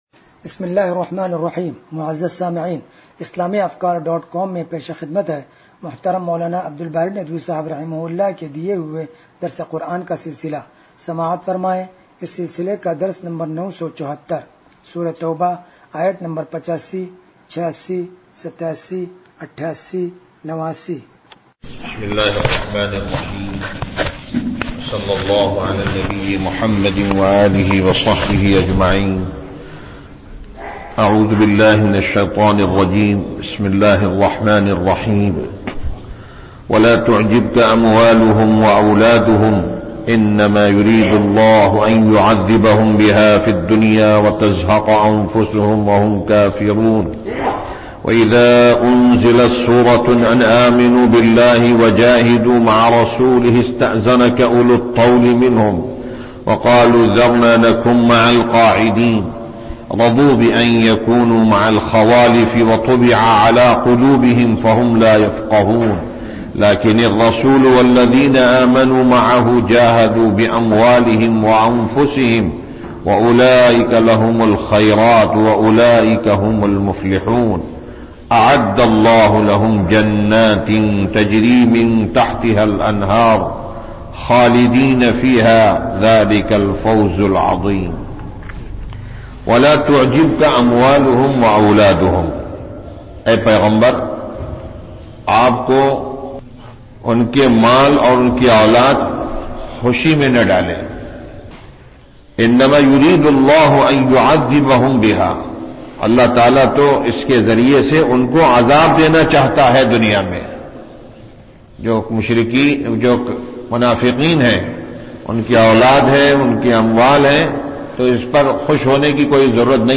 درس قرآن نمبر 0974